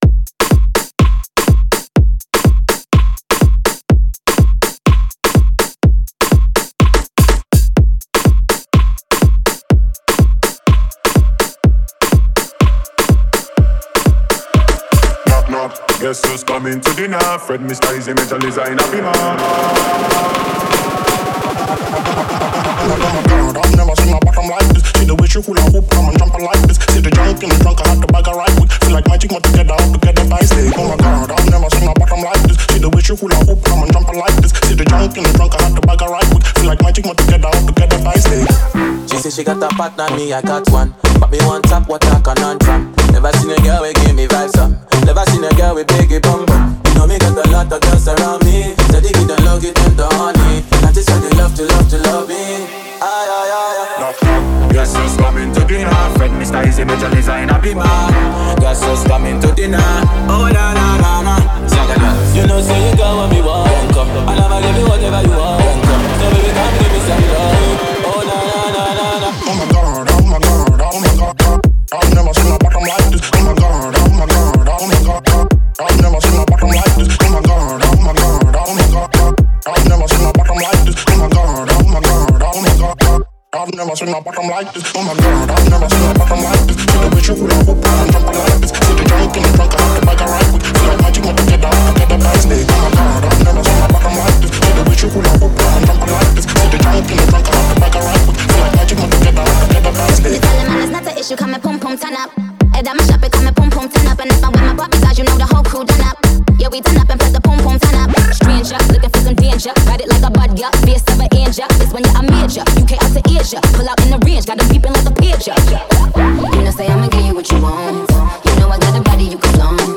MusicNigeria Music
electronic dance music trio